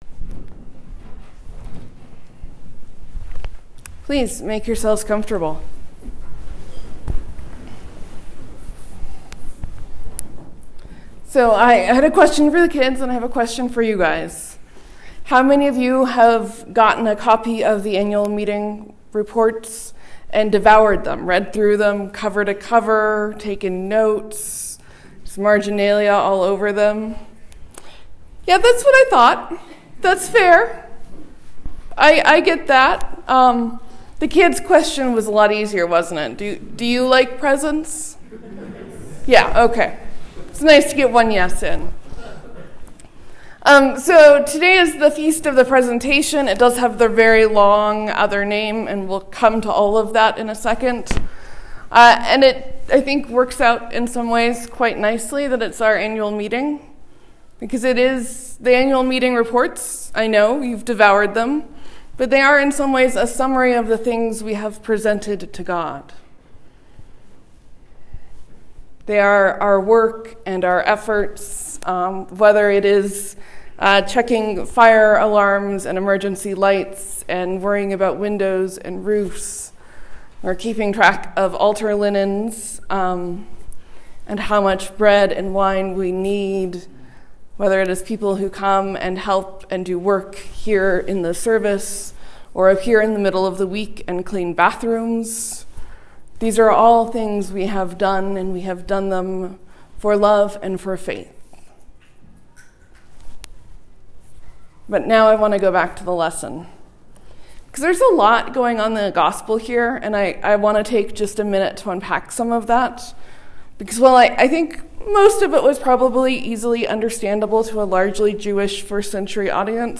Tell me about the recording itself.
When the Feast of the Presentation is on the same day as your Annual Meeting, you get to preach on Mary, Joseph, and all the things your parish offers to God.